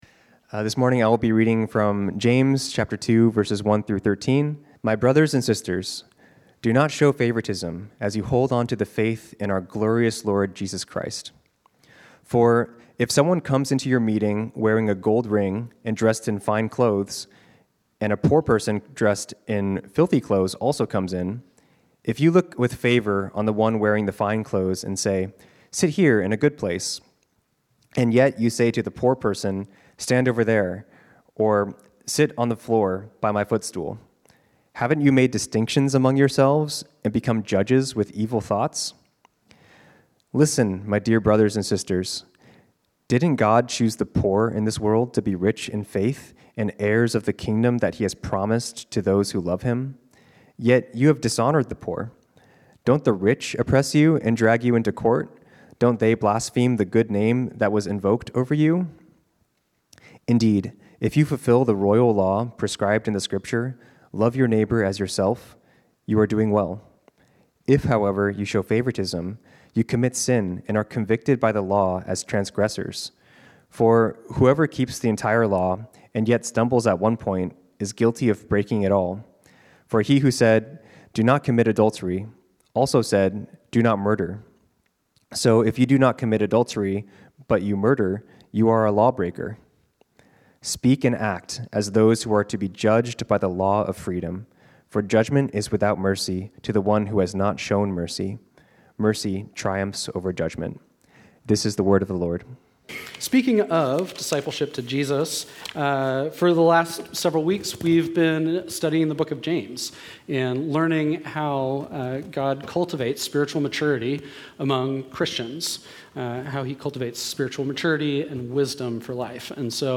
This sermon was originally preached on Sunday, October 19, 2025.